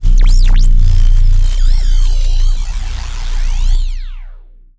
sci-fi_electric_pulse_hum_07.wav